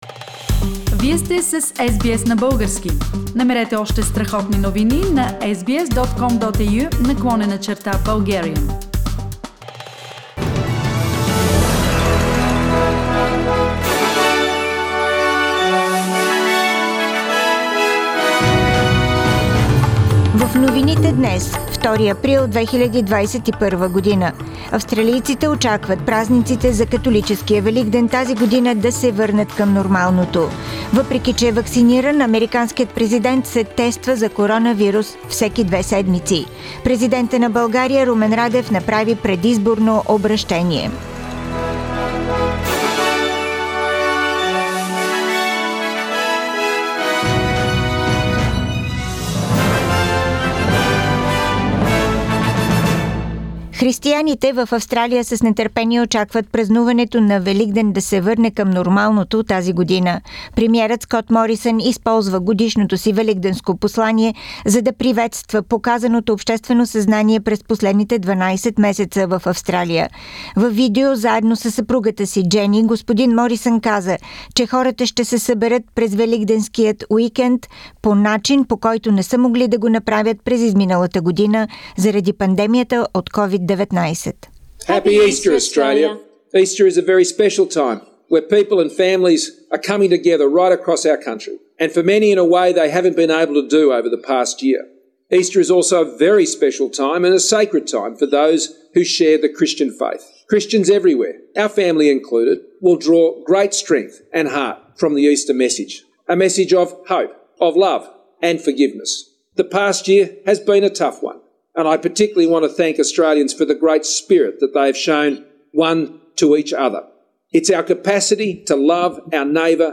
Weekly Bulgarian News – 2nd April 2021